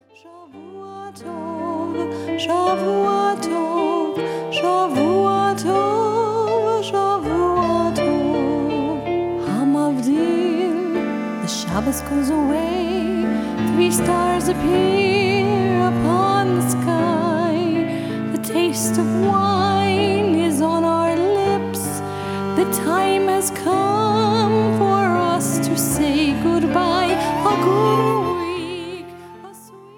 Recorded with top Israeli session players.